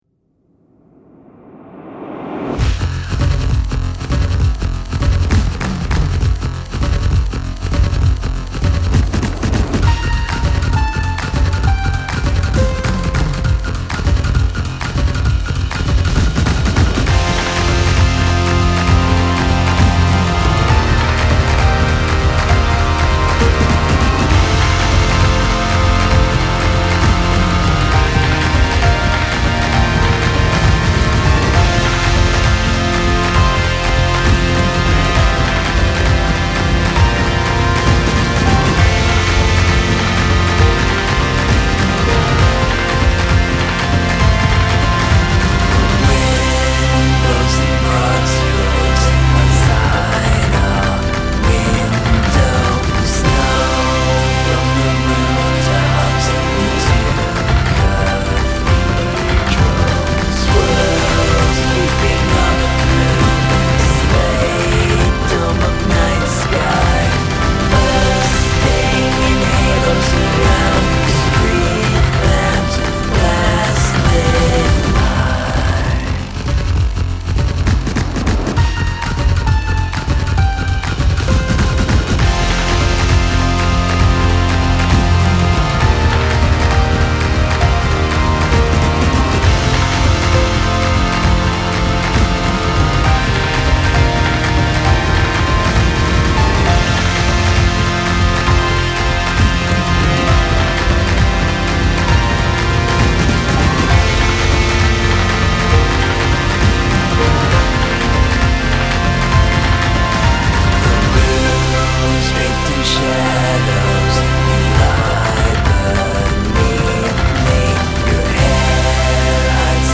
electronic, hip hop fused pop symphonies